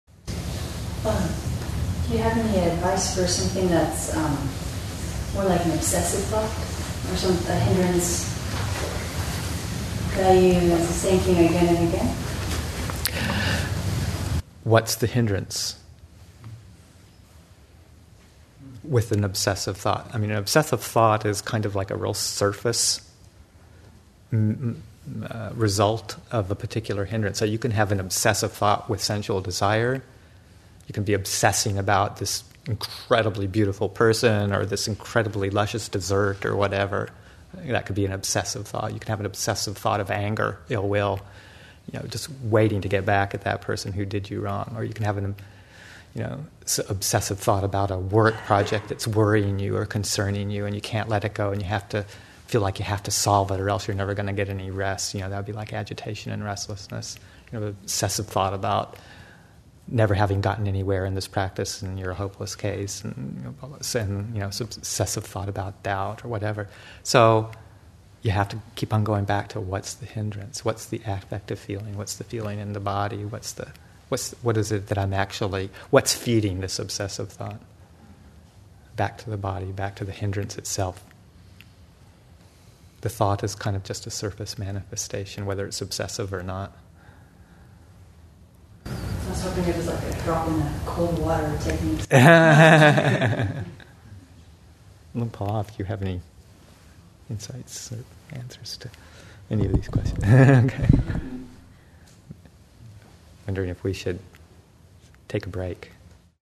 Jhāna: A Practical Approach, Session 2 – Oct. 10, 2015